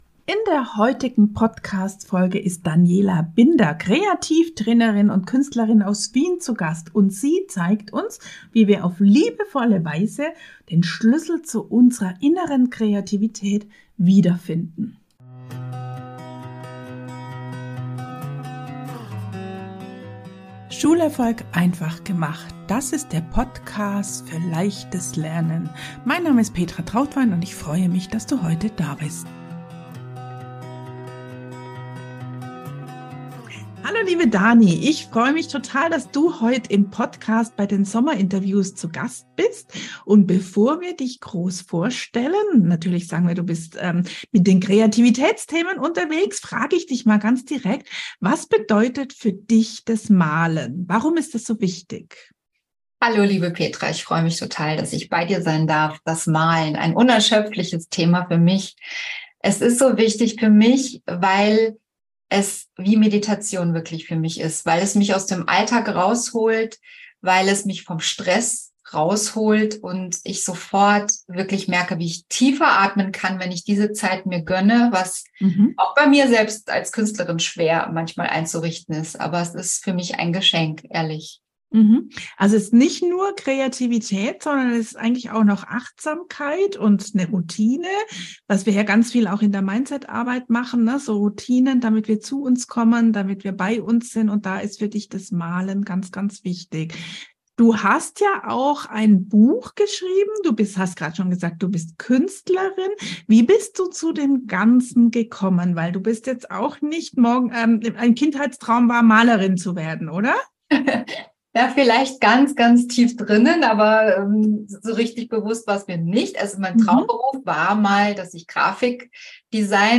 In diesem Sommerinterview dreht sich alles um die Magie des Malens und wie das Malen uns auf liebevolle Weise unterstützen kann, den Schlüssel zu unserer inneren Kreativität wiederzufinden.